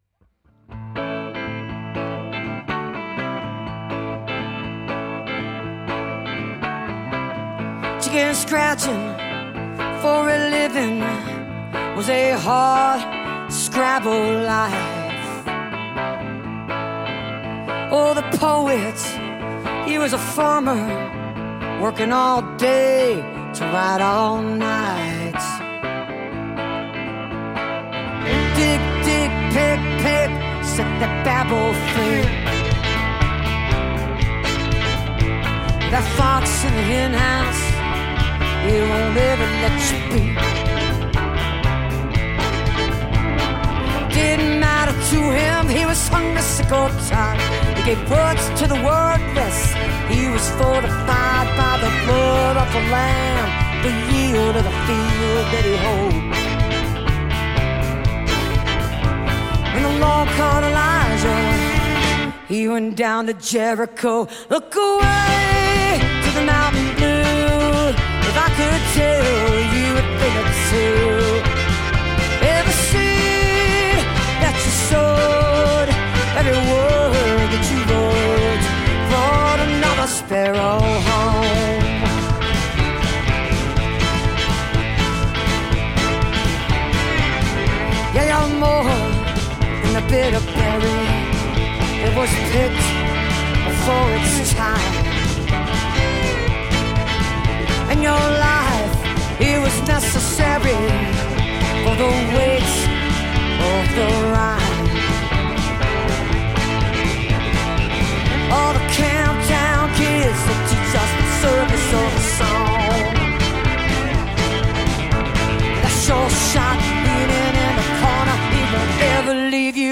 (recorded from a webcast)